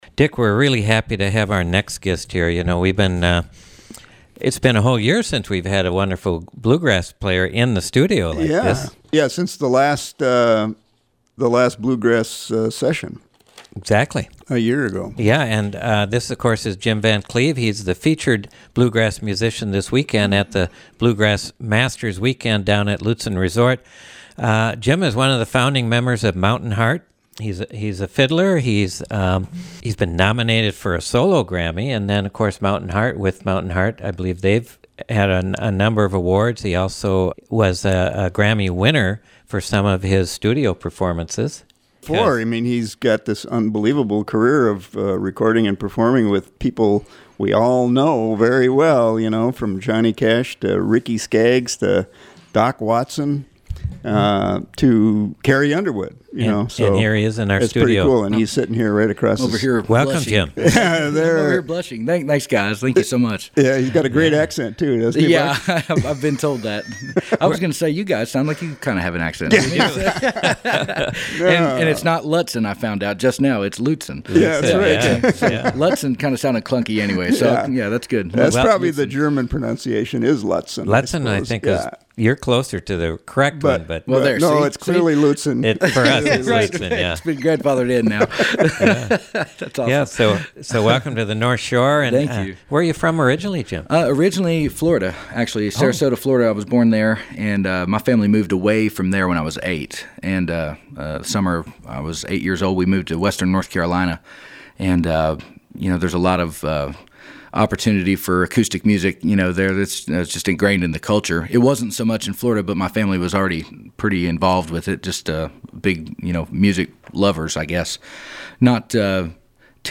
Fiddle player & songwriter
Incredible fiddle music! Program: The Roadhouse